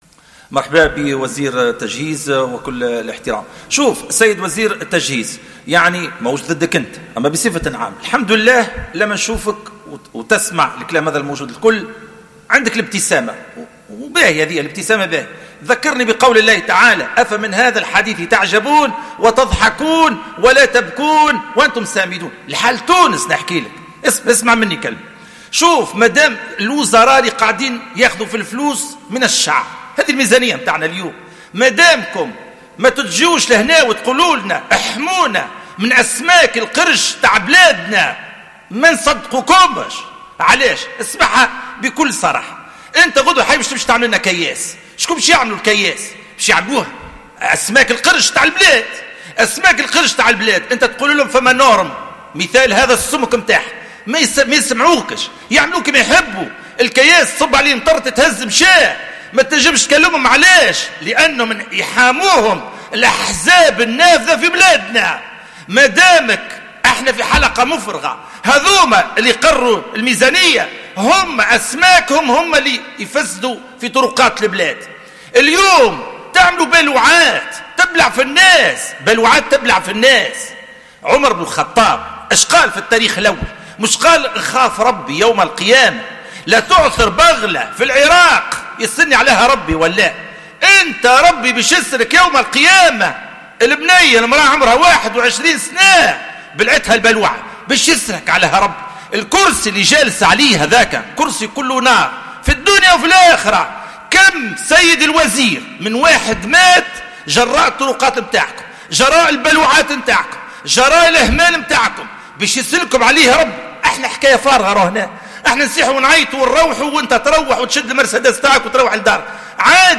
أكد النائب سعيد الجزيري في مداخلته صباح اليوم الخميس خلال الجلسة المخصصة لمناقشة مشروع ميزانية وزارة التجهيز والإسكان والبنية التحتية، أنّ الحالة الكارثية للطرقات ألقت بالمواطنين في "البالوعات" حتى الموت، حسب قوله.